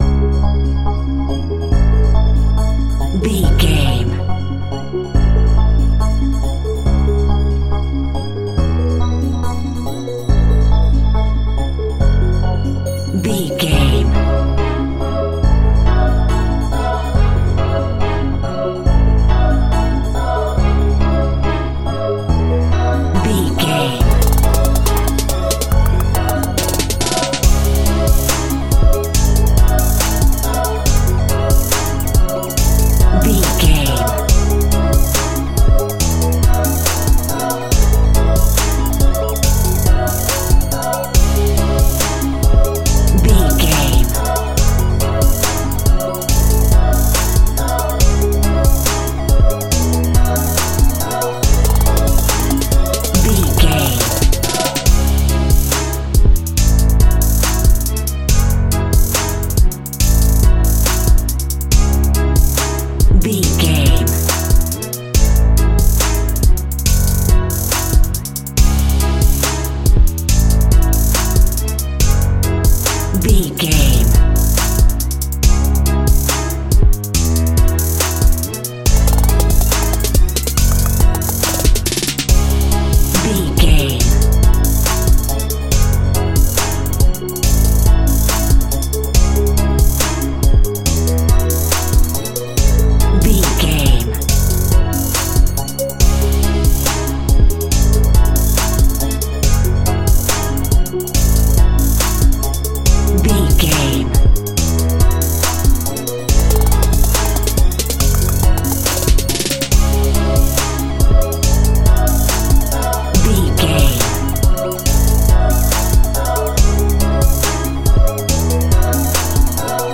Hip Hop Modern Chart Cue.
Aeolian/Minor
C#
Fast
instrumentals
chilled
laid back
groove
hip hop drums
hip hop synths
piano
hip hop pads